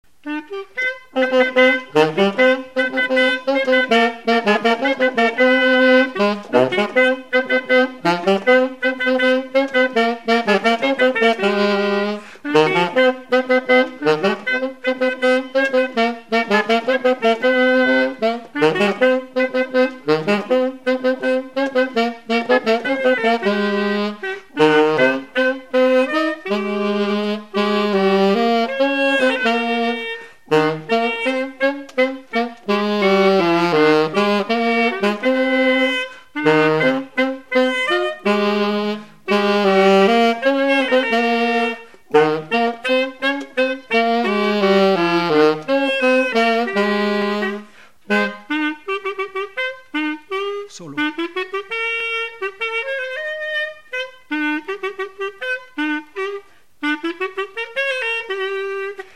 instrumental
Pays Sud-Vendée
Chansons traditionnelles et populaires